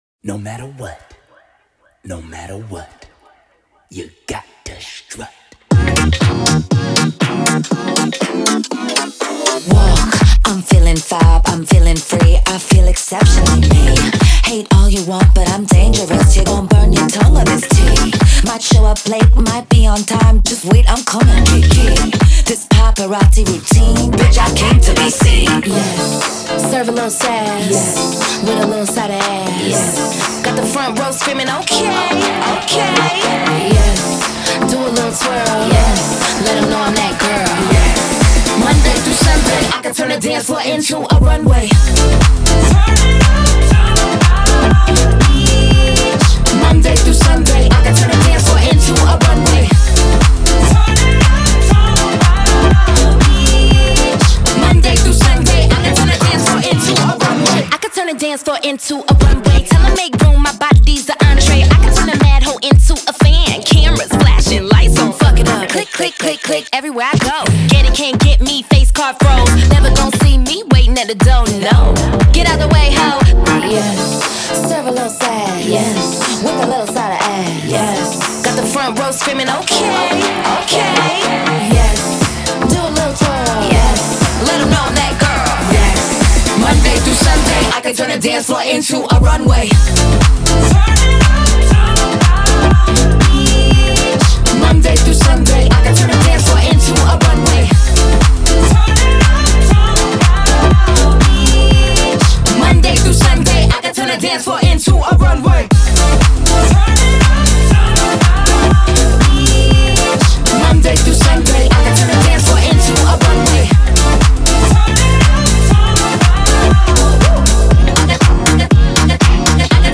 BPM120-120
Audio QualityPerfect (High Quality)
Full Length Song (not arcade length cut)